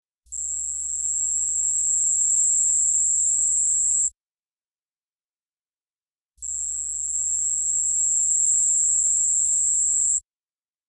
亮褐异针蟋